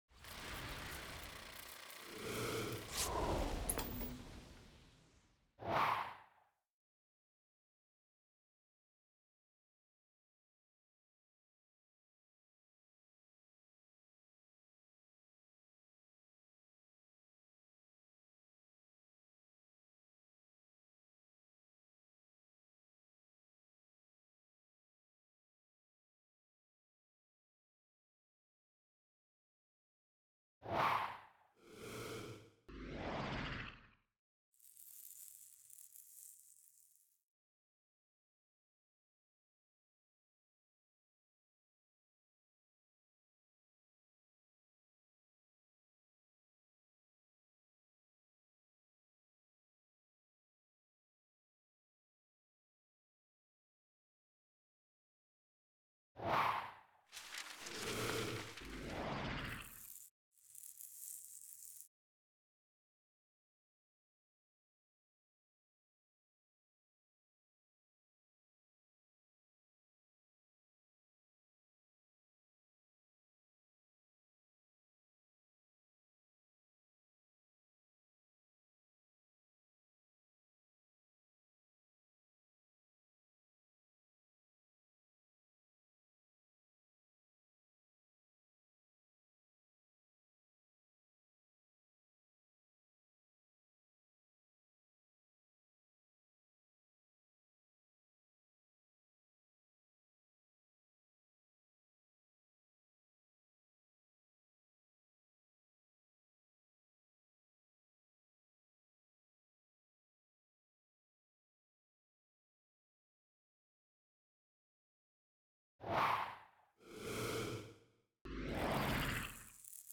BAV_S2S_EP3_ST SFX.wav